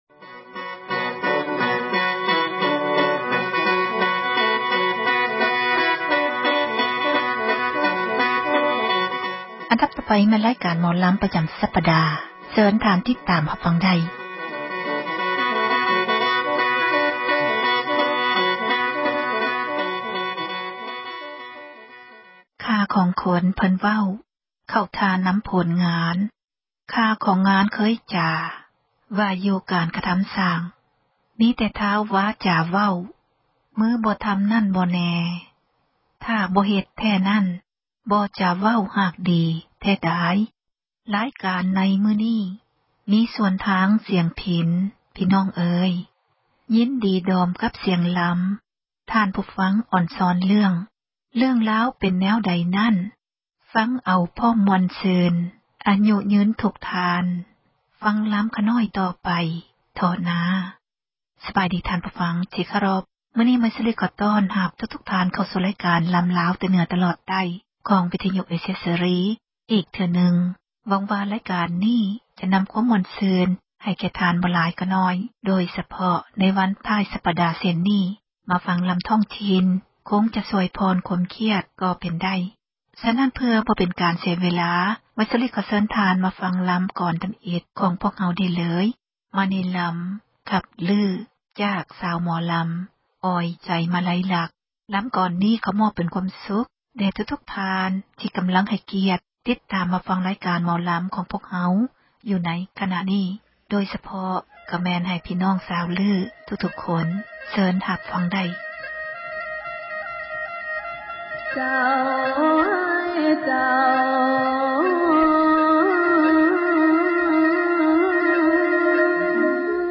ຣາຍການ ໝໍລໍາ ແຕ່ເໜືອ ຕລອດໃຕ້ ຈັດມາສເນີທ່ານ